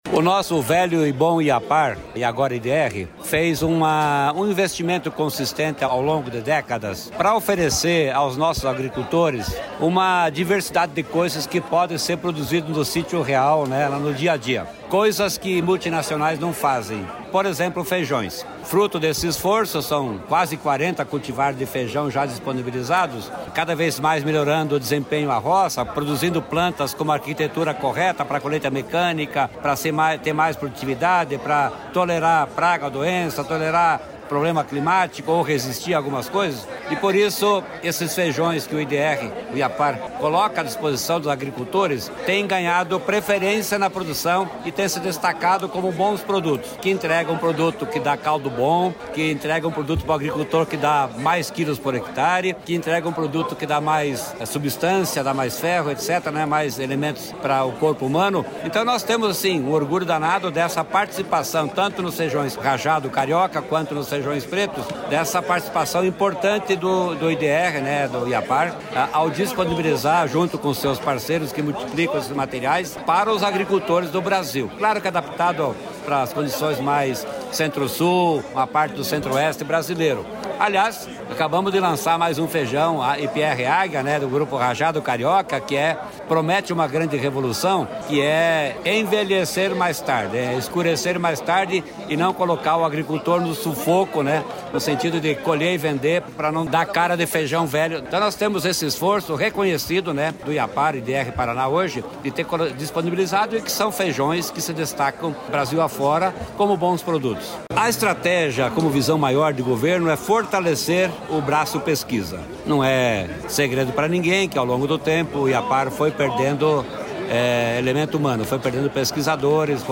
Sonora do secretário da Agricultura e do Abastecimento, Norberto Ortigara, sobre o protagonismo do Paraná na produção de feijão